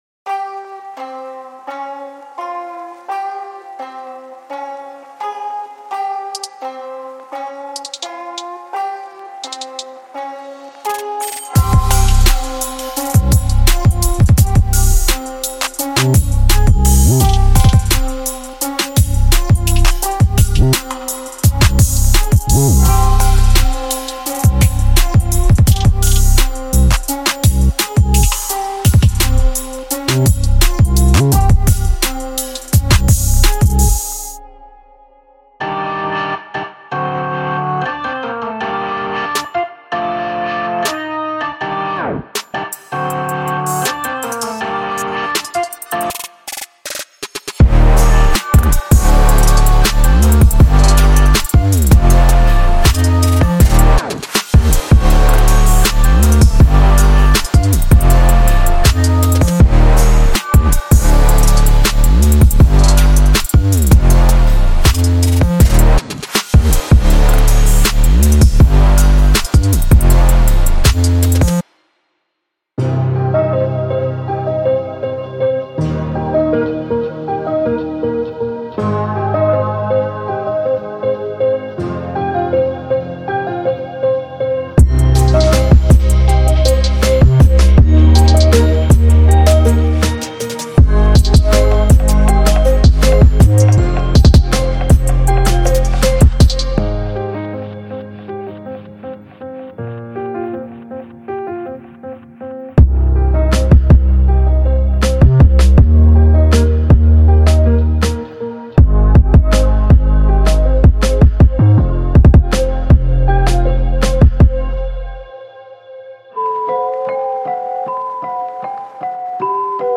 鼓采样，循环和旋律 将帮助您开始制作，并从一开始就获得最佳节奏。
•7个KILLER 808s –您需要的所有
••11个硬•击中SNARES
•15个打击乐–振动器，轮辋和拍子•15
•24个鼓循环，分别以80bpm，110bpm，140bpm和160bpm